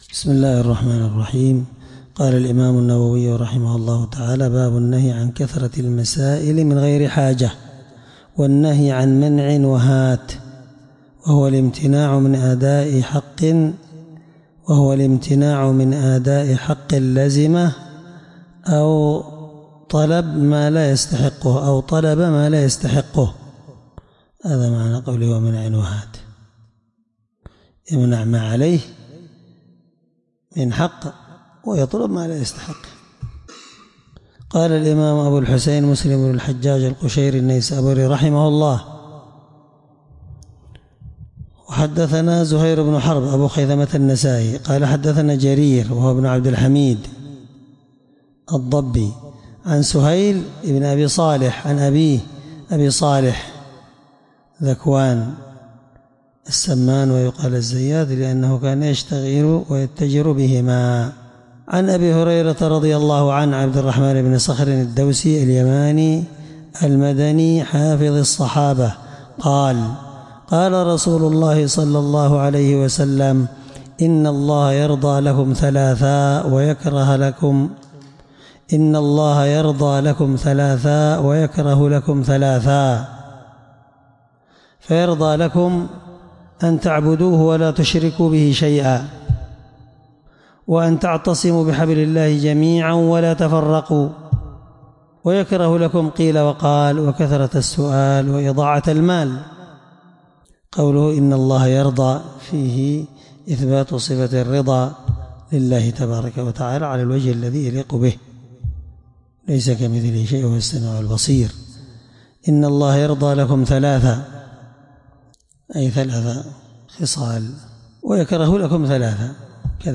الدرس5من شرح كتاب الأقضية الحدود حديث رقم(1715) من صحيح مسلم